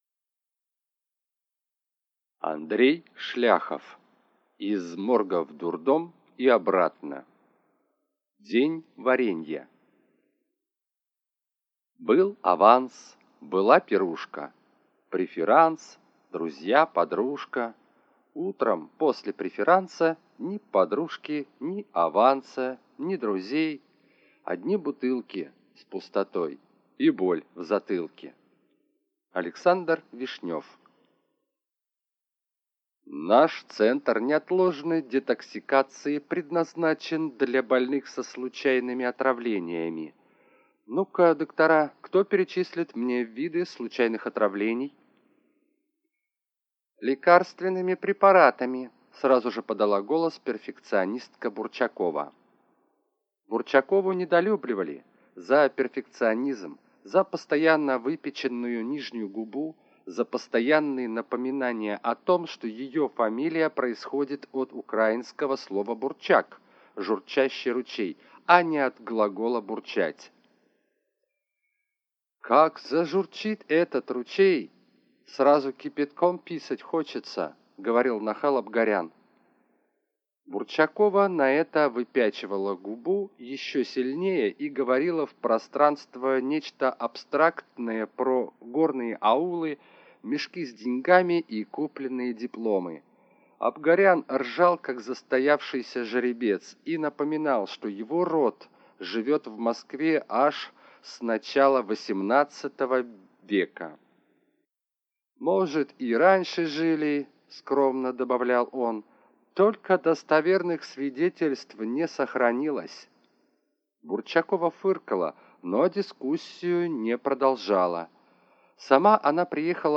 Аудиокнига Из морга в дурдом и обратно (сборник) - купить, скачать и слушать онлайн | КнигоПоиск